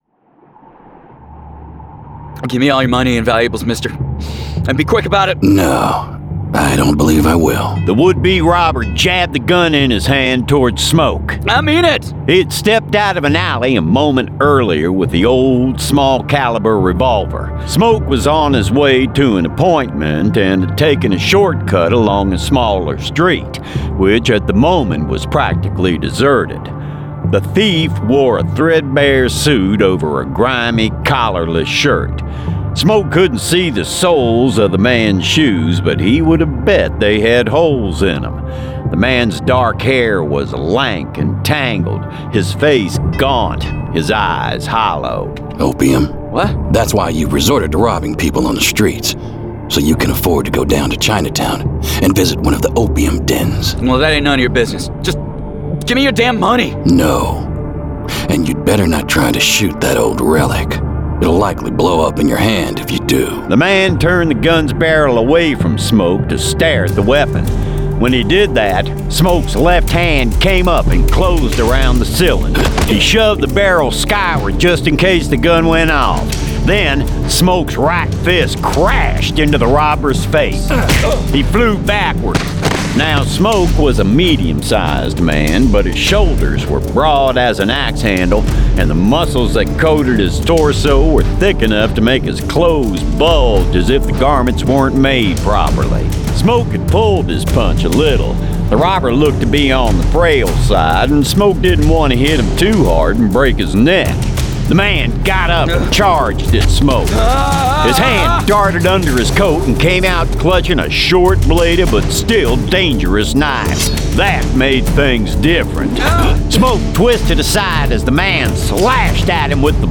Full Cast. Cinematic Music. Sound Effects.
[Dramatized Adaptation]
Genre: Western